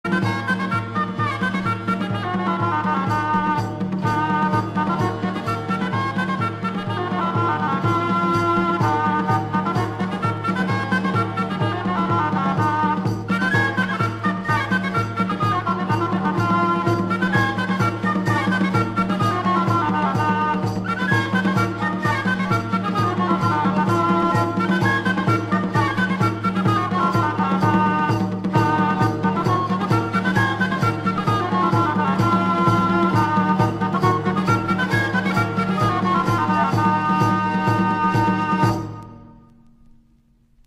So ähnlich könnte es beim Tanz geklungen haben:
Tanzmusik_3.mp3